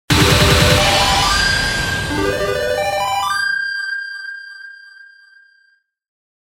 • 昇格演出中/Victory7図柄獲得音